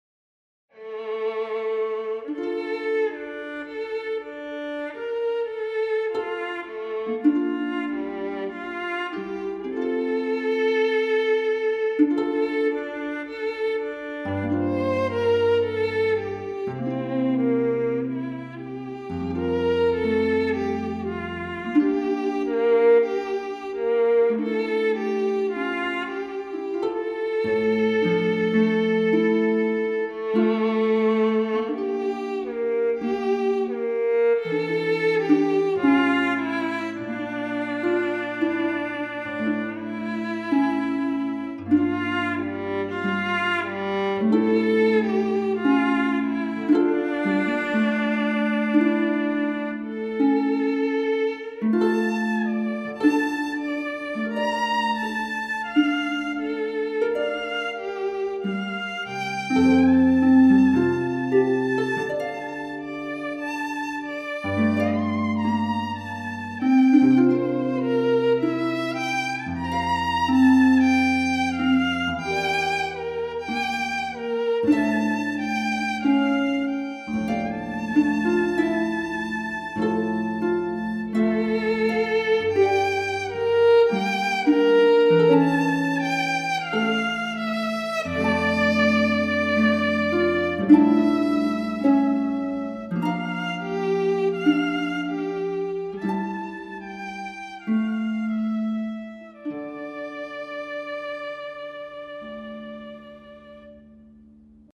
violin and
harp